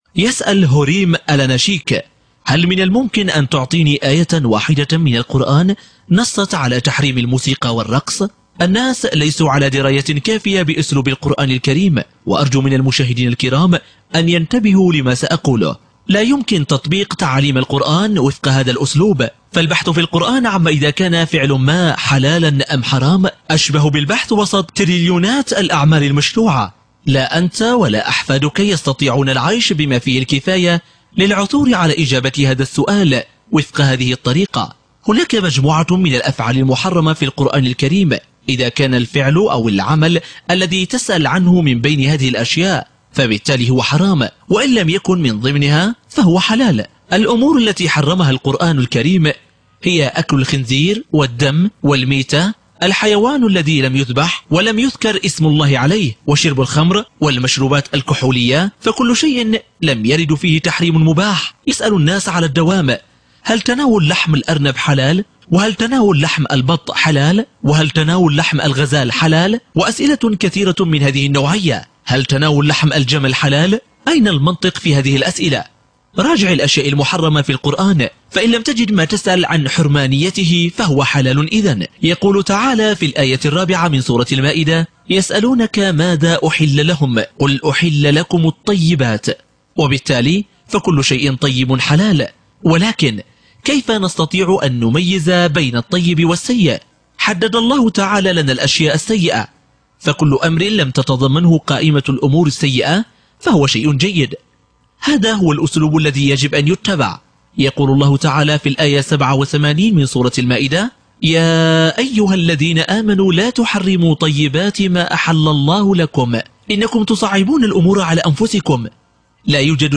مقتطفات من حوار للسيد عدنان أوكتار المباشر على قناة A9TV المُذاع في 11 مايو/ أيار 2016 عدنان أوكتار